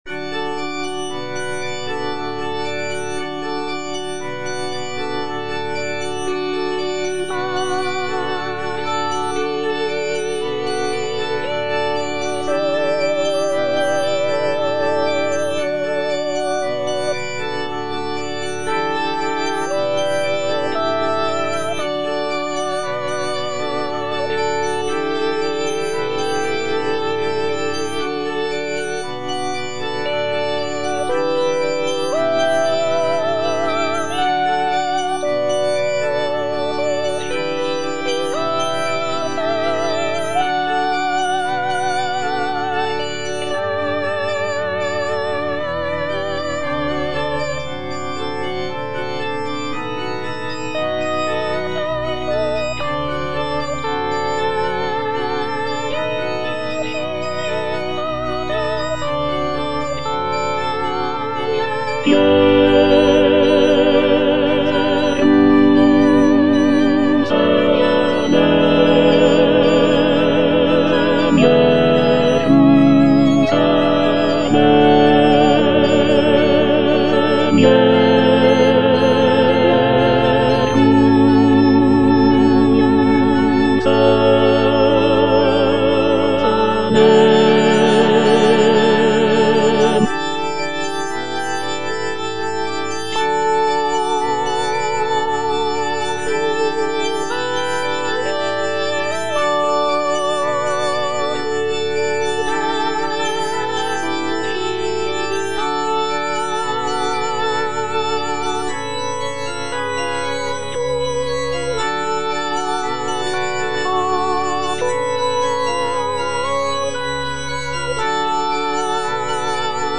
version with a smaller orchestra
(tenor I) (Emphasised voice and other voices) Ads stop